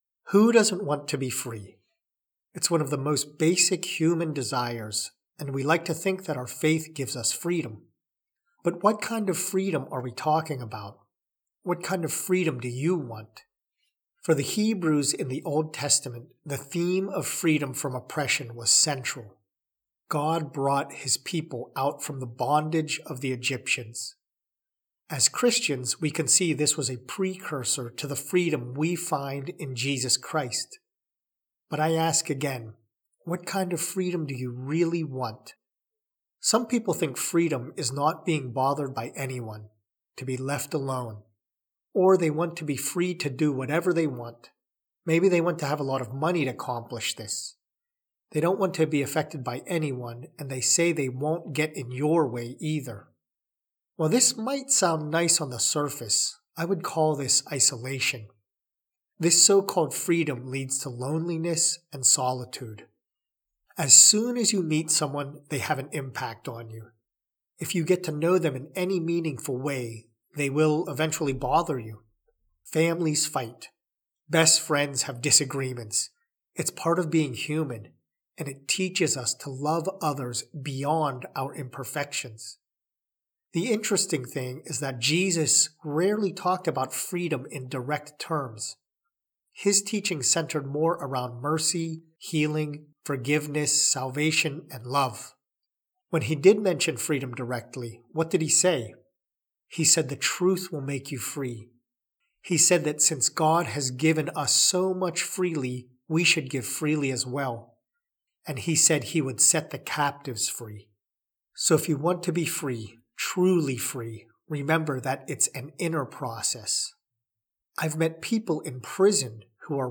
prayer-to-break-free.mp3